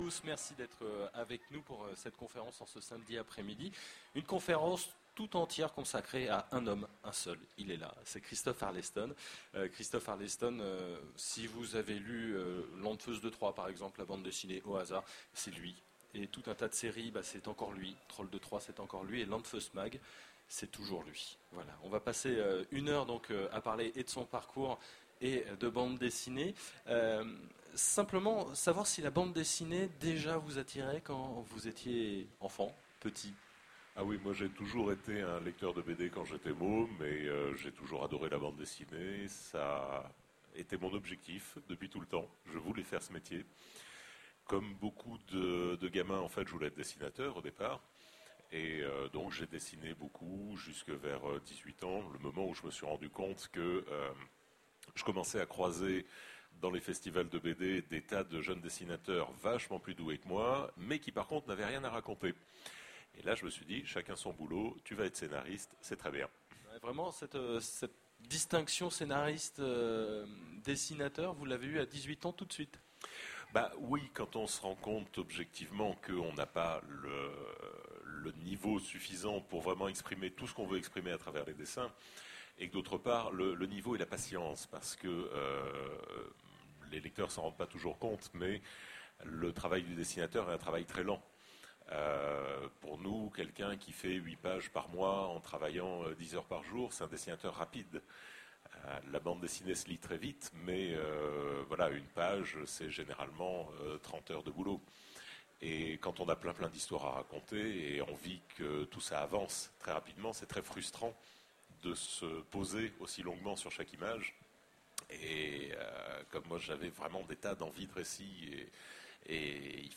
Voici l'enregistrement de la conférence/rencontre avec Christophe Arleston aux Utopiales 2010. Découvrez son parcours et ses œuvres au travers de cet entretien.